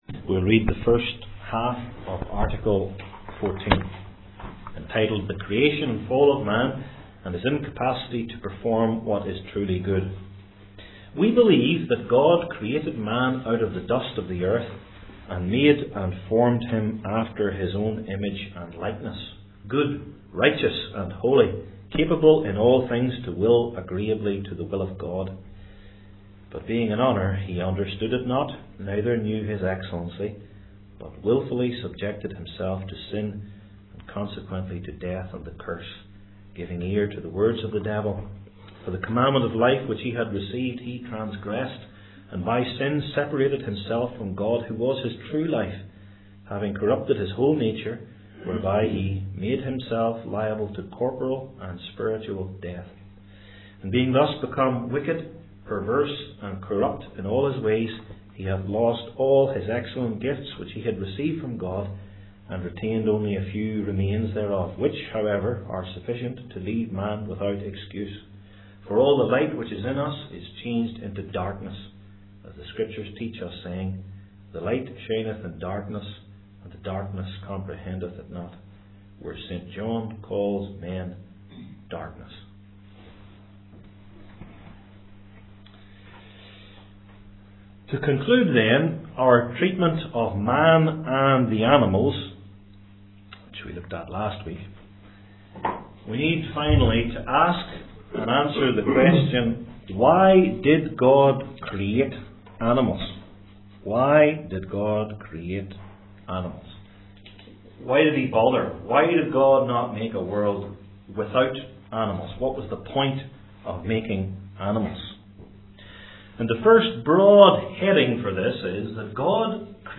Passage: Genesis 2:1-10 Service Type: Belgic Confession Classes